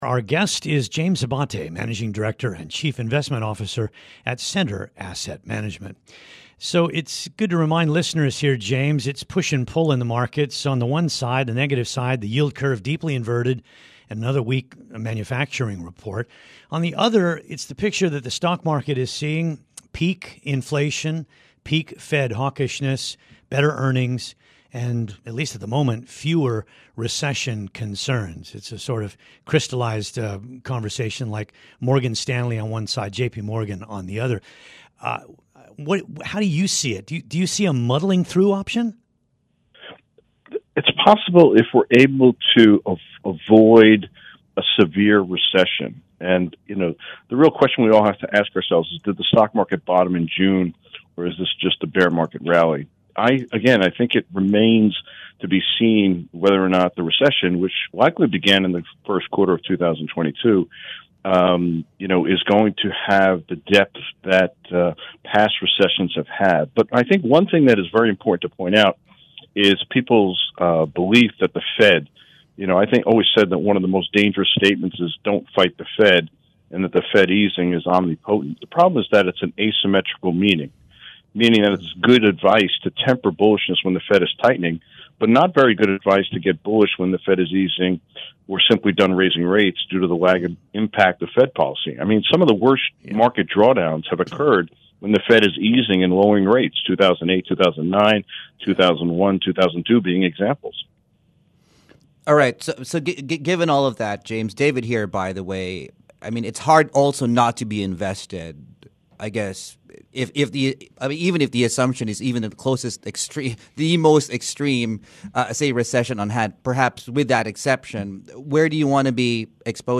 He spoke with hosts